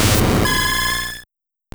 Cri d'Octillery dans Pokémon Or et Argent.